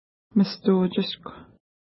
Pronunciation: mistu:tʃəʃkw
Pronunciation